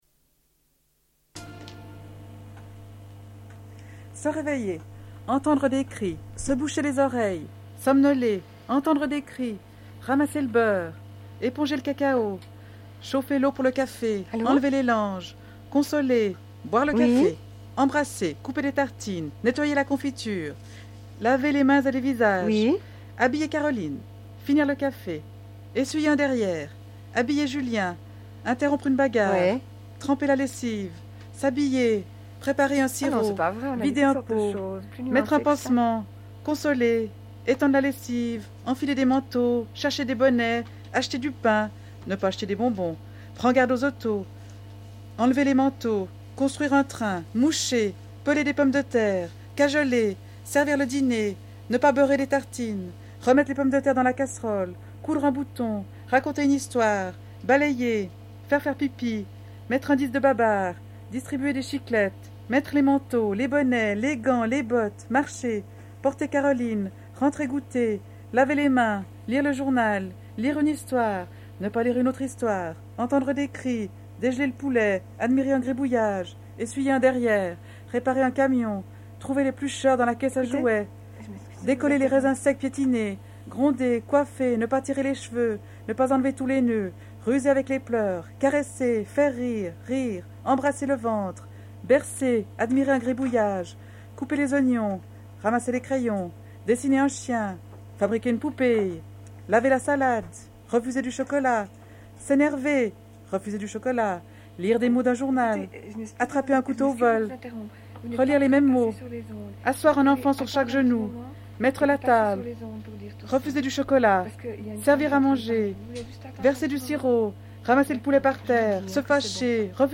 Pour commencer l'année 1983, RPL organise un « marathon » de 24h de radio.
Téléphone avec un homme sur le salaire au travail ménager et la prise en charge de ce travail, le bénévolat et le travail salarié. Discussion entre les animatrices sur la question de la rémunération.
Une cassette audio.
Lecture d'un texte d'un groupe de travailleur·euse·s sociaux.